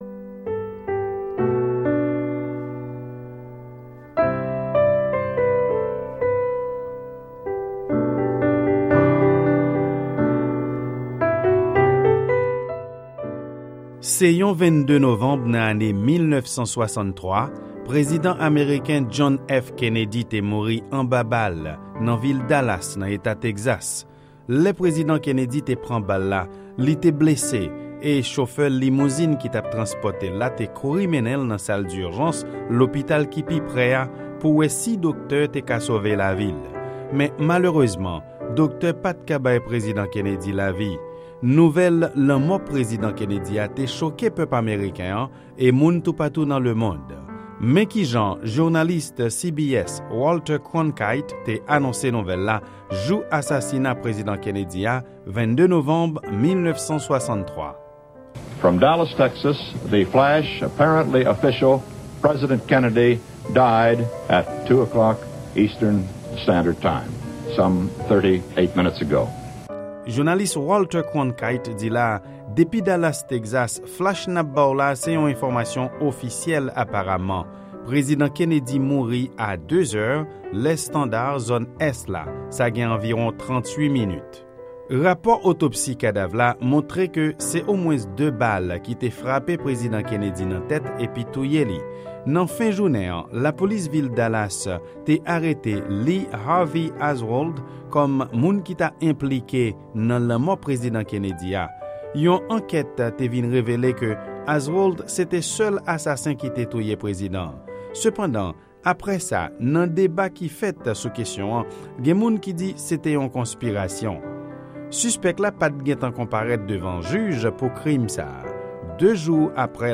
Yon repòtaj Lavwadlamerik